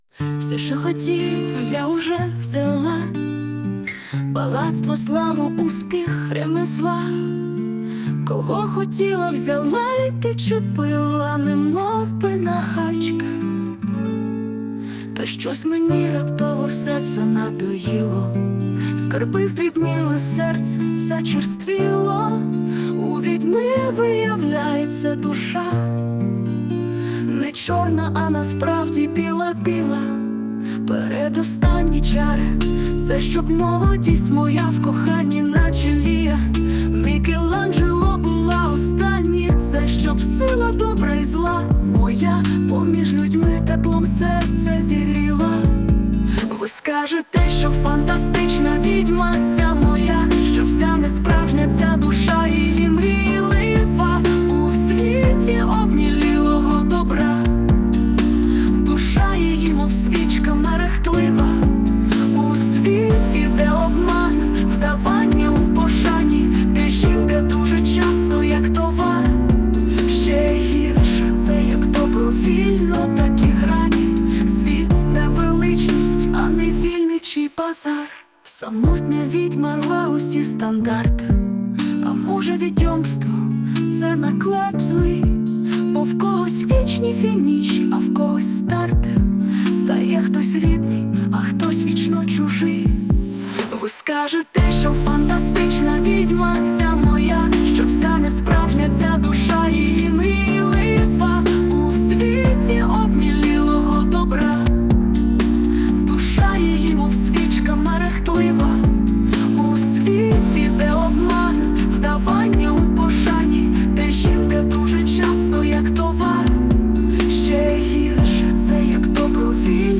Музичний супровід створено з допомогою ШІ
СТИЛЬОВІ ЖАНРИ: Ліричний